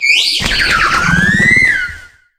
Cri de Favianos dans Pokémon HOME.